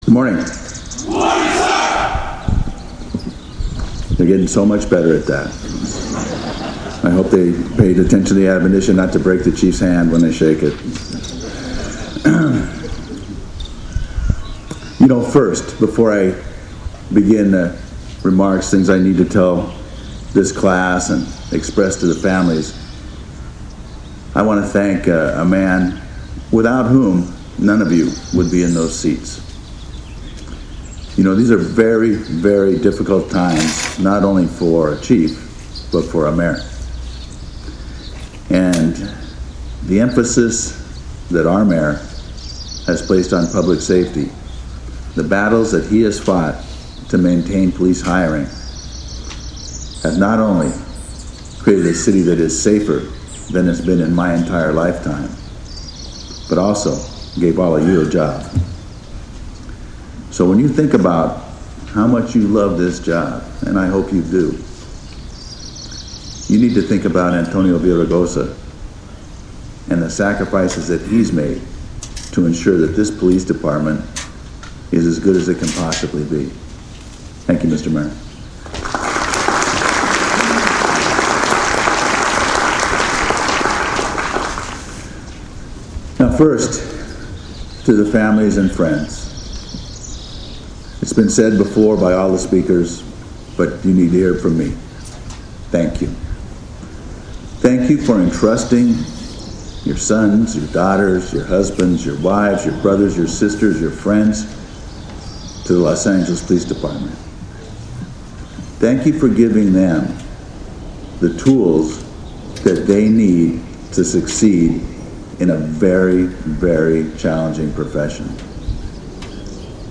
To hear Police Chief Charlie Beck address the new class,click here
March 22, 2025 – On the athletic field at the Los Angeles Police Academy in Elysian Park, the newest members of the Department proudly stood at attention as they were presented with their diploma of graduation.